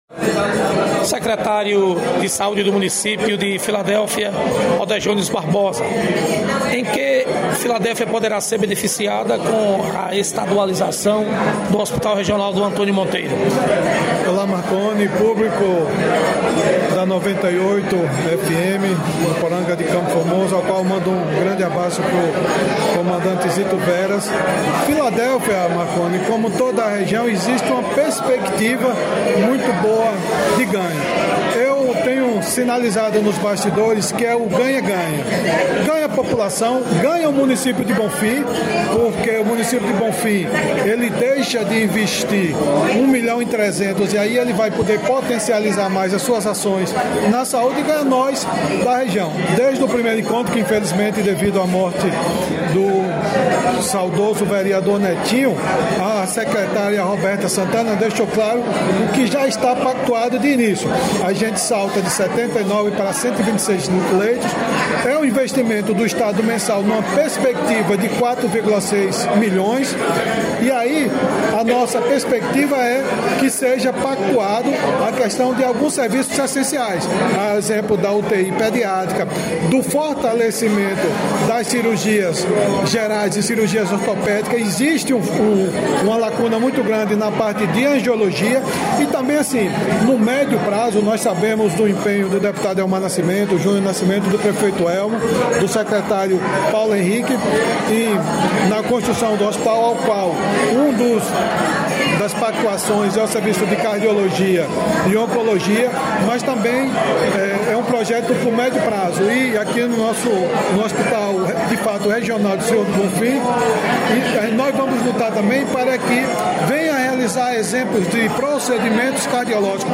Secretário de saúde do município de Filadélfia, Odejones Barbosa – Encontro pra estadualização do hospital regional de Sr. do Bonfim